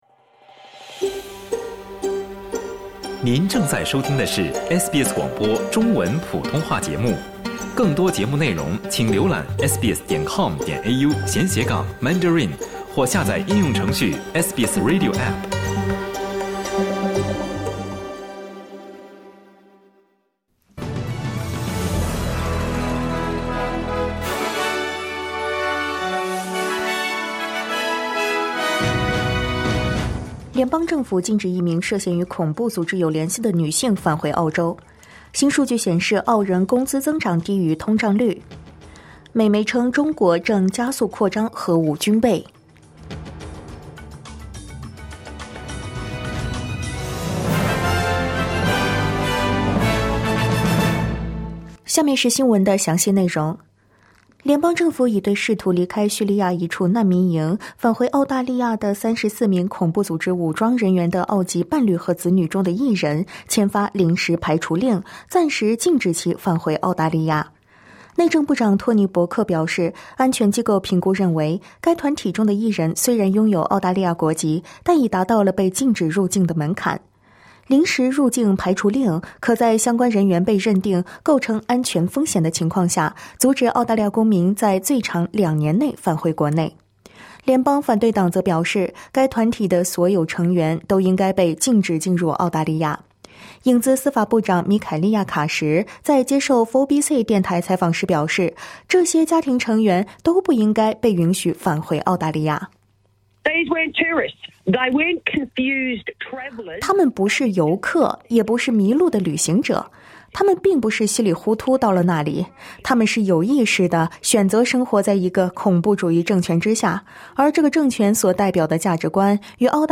【SBS早新闻】美媒称中国正加速扩张核武军备